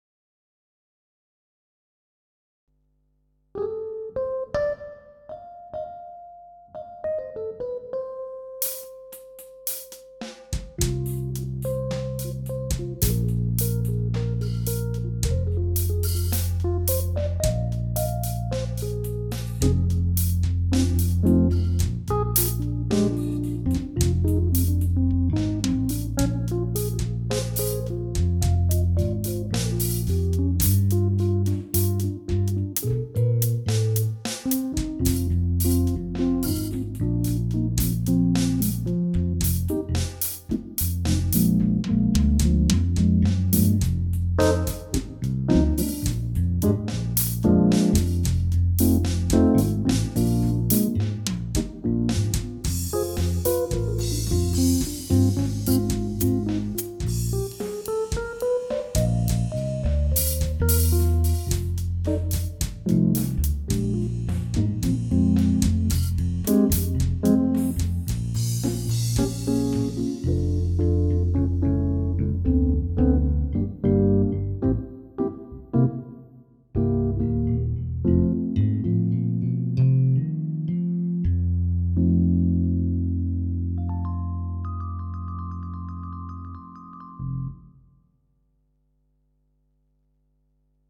To test some setup I made with bass/drums and e-piano, through a double Lexicon effect, mixed straight into my power amp's pre-amp with Lexicon Omega, I recorded a not overly complicated drum track, played it back, and played live bass and e-piano, like anyone should care. Bass and drum on a semi-weighted 76 keys board, e-piano on a fully weighted 88.
No overdubs (apart from pre-sequenced drum, no quantize or edits), apart from double Lexicon (analog and digital inputs used) and instrument-effects no additional processing of any kind, played live:
Well, those sounds are getting fun enough, though of course there are people who prepare major Fusion shows and prepare songs and practice properly, and hopefully find time to mix more accurately, but I called it: A Jam.